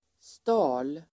Ladda ner uttalet
Uttal: [sta:l]